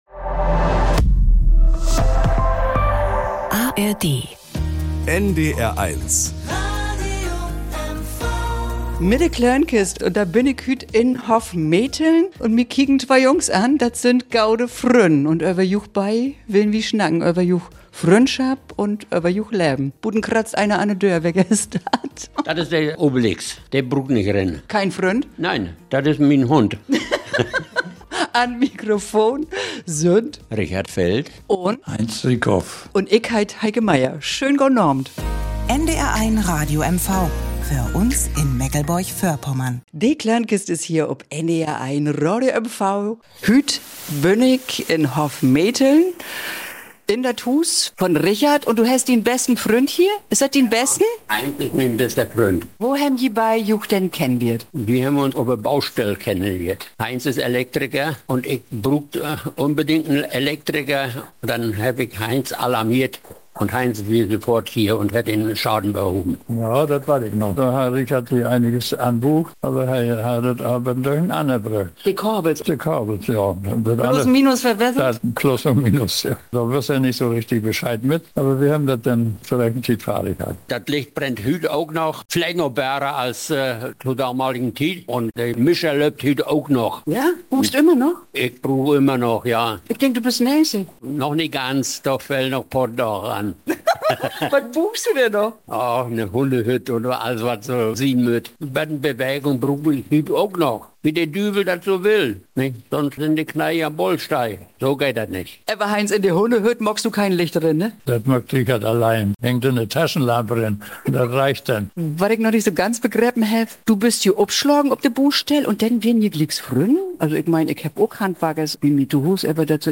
Fast 90 sind sie nun und ihrer Geschichten noch lang nicht müde. Und die Geschichten haben es in sich: Eine handelt zum Beispiel davon, wie das Haus von Schriftstellerin Christa Wolf abgebrannt ist.